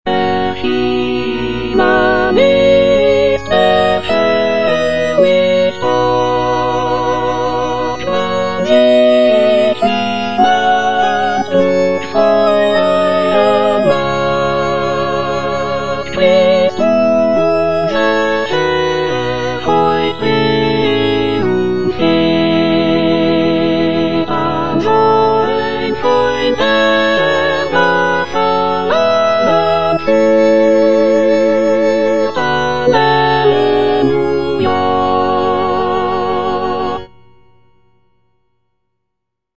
Cantata
Soprano (Emphasised voice and other voices) Ads stop